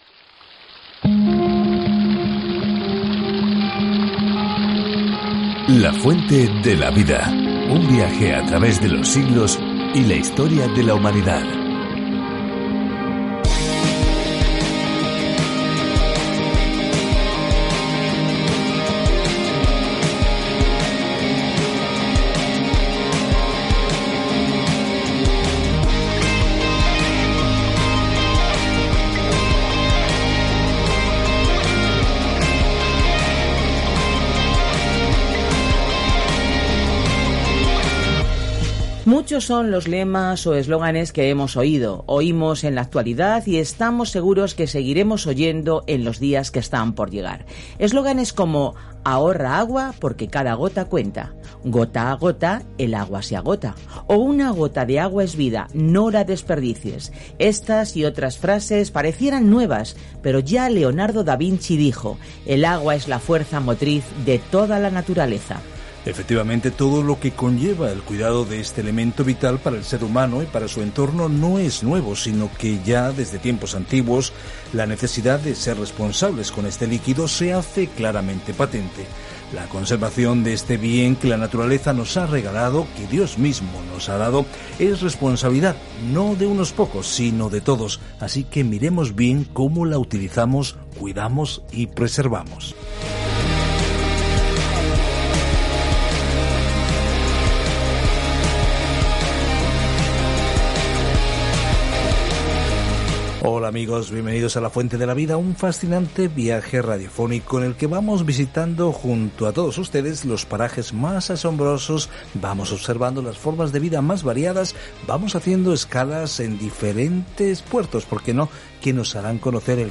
Escritura MIQUEAS 1:1-2 Iniciar plan Día 2 Acerca de este Plan En una hermosa prosa, Miqueas llama a los líderes de Israel y Judá a amar la misericordia, actuar con justicia y caminar humildemente con Dios. Viaja diariamente a través de Miqueas mientras escuchas el estudio de audio y lees versículos seleccionados de la palabra de Dios.